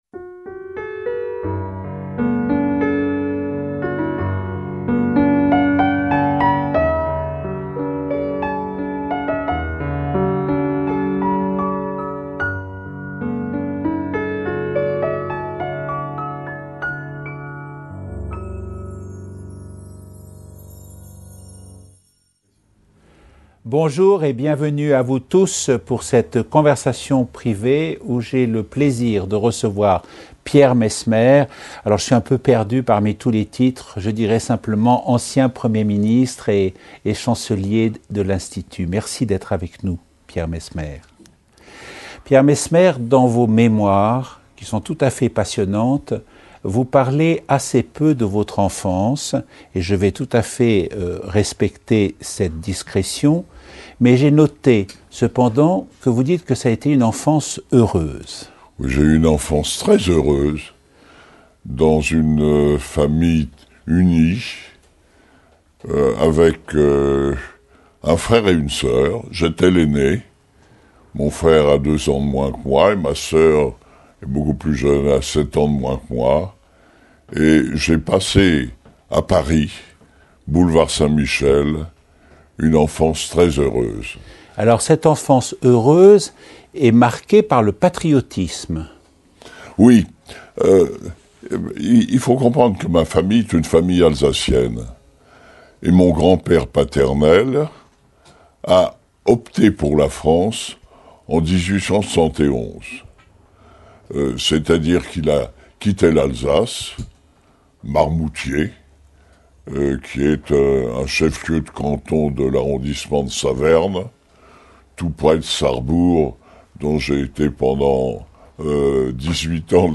Frédéric Mitterrand interviewe Pierre Messmer (1916-2007) au Palais de l’Institut de France pour l’émission « Conversations privées » de la chaîne TV5 Monde, diffusée le 25 août 2005.